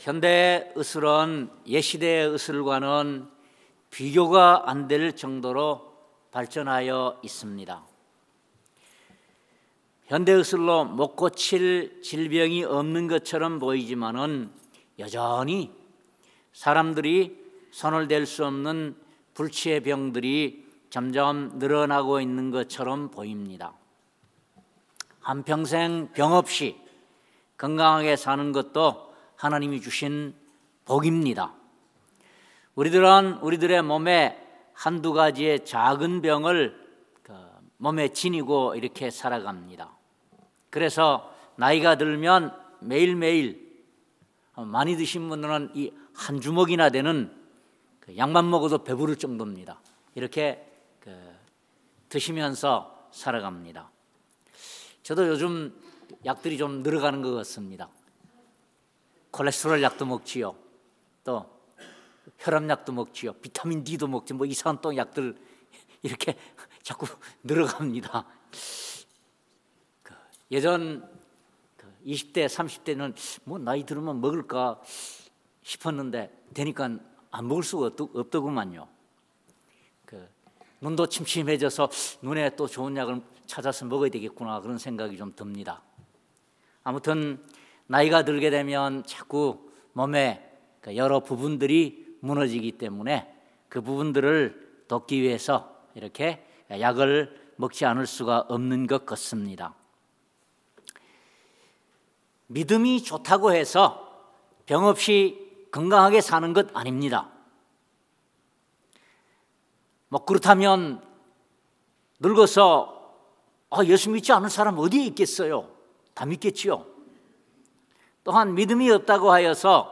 막 8:22-26 Service Type: 주일예배 첫째로 오늘 본문 말씀은 우리의 질병은 우리 주님의 능력에 의하여 단 한번의 안수로 낫기도 하지만